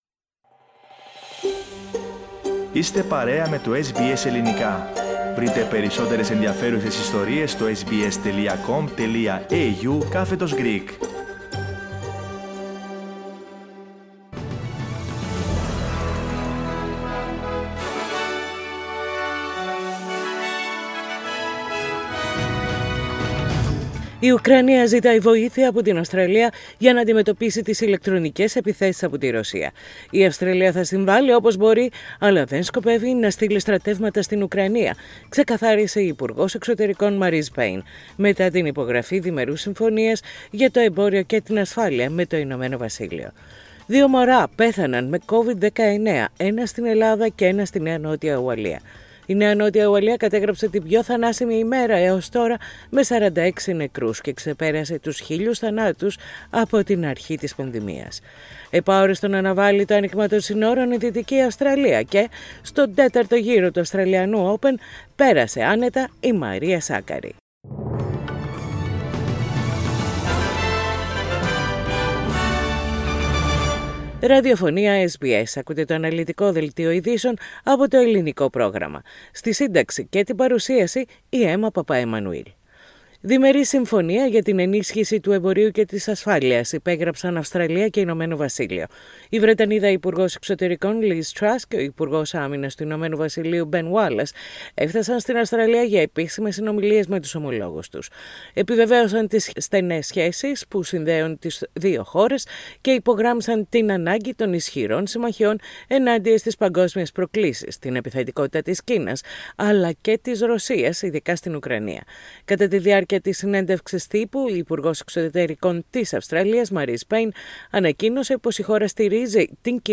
The main bulletin of the day with news from Australia, Greece, Cyprus and the international arena.